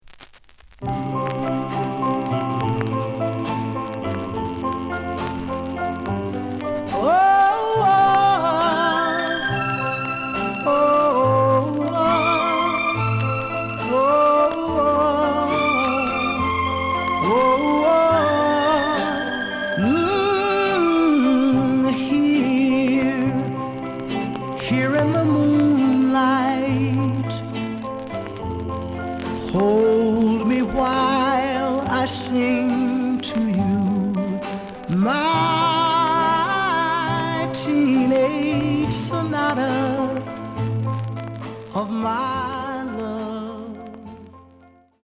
The listen sample is recorded from the actual item.
●Genre: Soul, 60's Soul
Some noise on both sides.